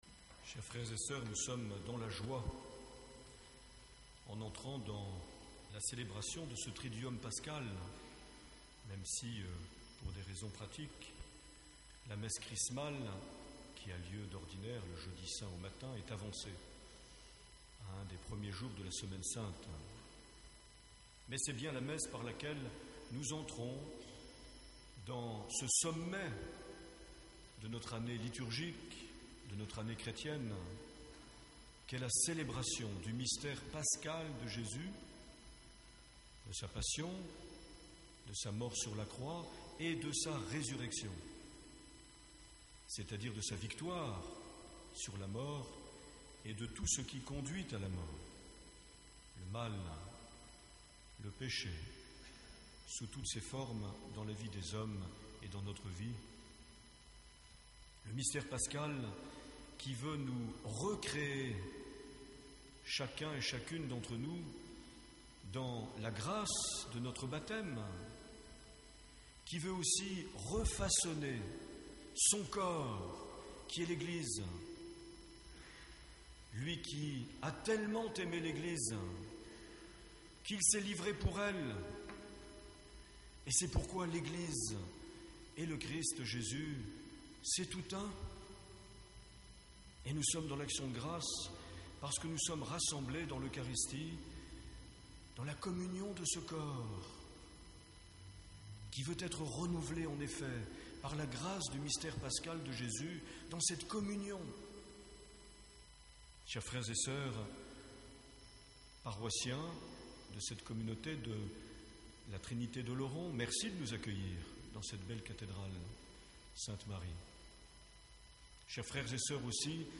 29 mars 2010 - Cathédrale d’Oloron-Sainte-Marie - Messe Chrismale
Une émission présentée par Monseigneur Marc Aillet